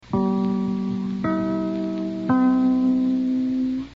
Click on the logo to hear the chimes